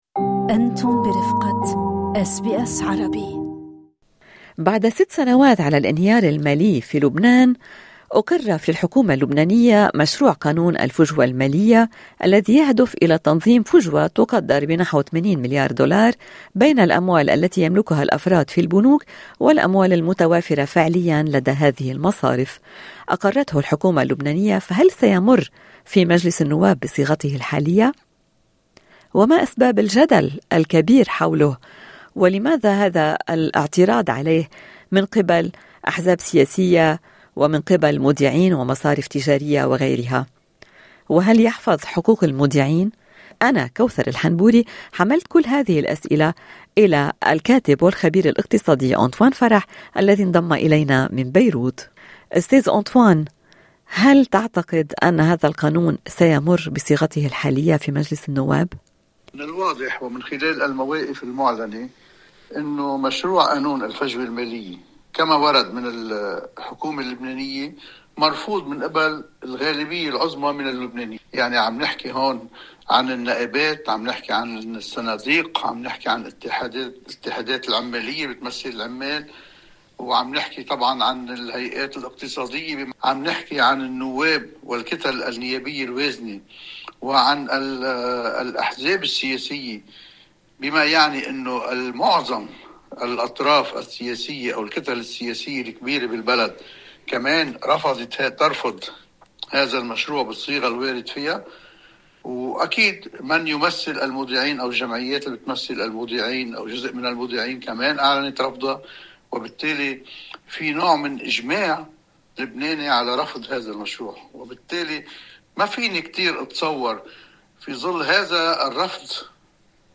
هل يمر قانون الفجوة المالية في مجلس النواب اللبناني؟ "لا يضمن حقوق المودعين": خبير يشرح